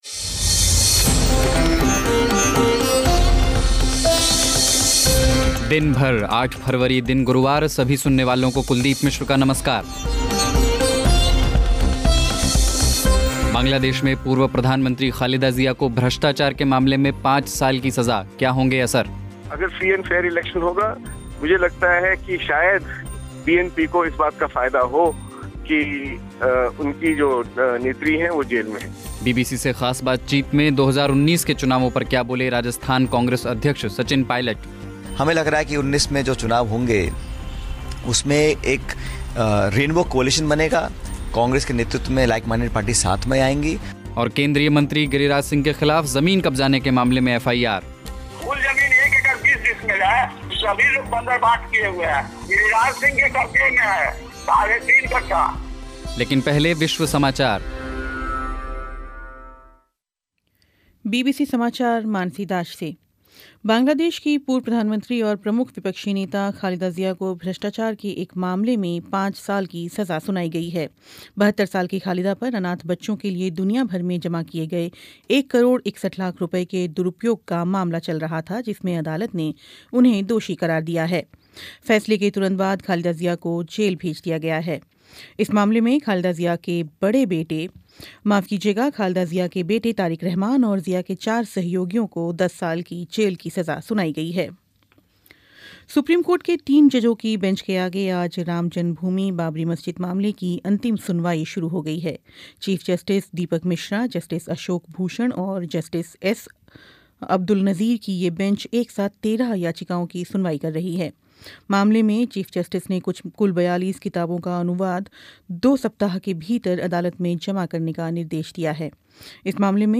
बीबीसी से ख़ास बातचीत में 2019 के चुनावों पर क्या बोले राजस्थान कांग्रेस अध्यक्ष सचिन पायलट